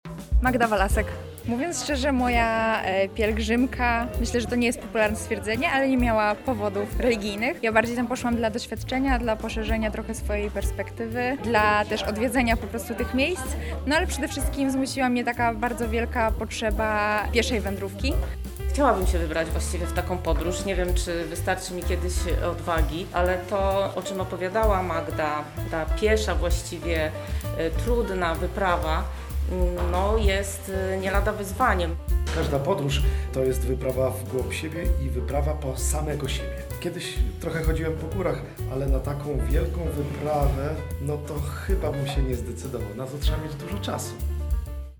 Za nami spotkanie podróżnicze w Akademickim Centrum Kultury i Mediów Chatka Żaka.